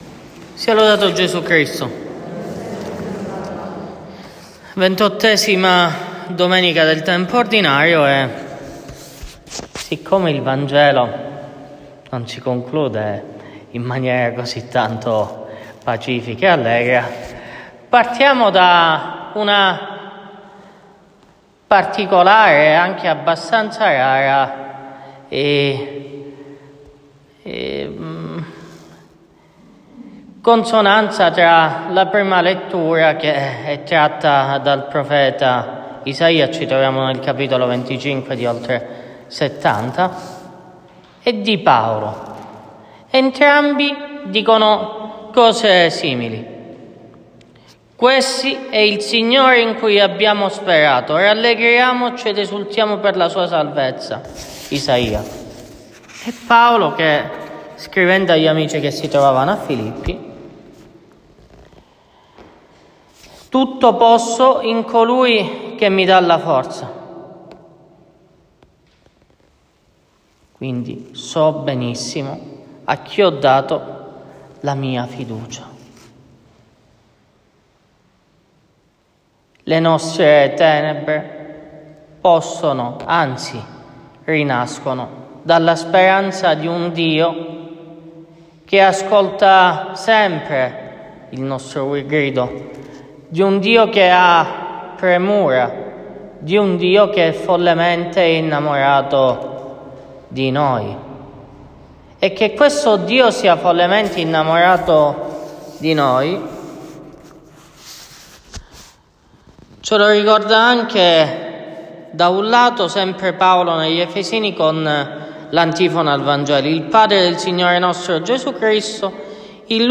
Omelia della XXVIII domenica del Tempo Ordinario